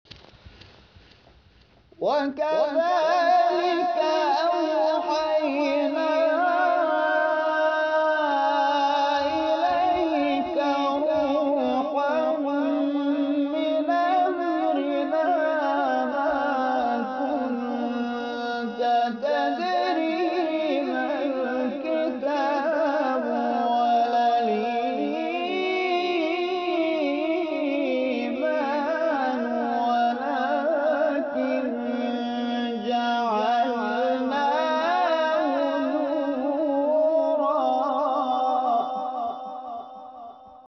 گروه شبکه اجتماعی: نغمات صوتی از تلاوت قاریان ممتاز و بین‌المللی کشور را می‌شنوید.